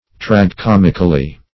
Tragi-comic \Trag`i-com"ic\, Tragi-comical \Trag`i-com"ic*al\,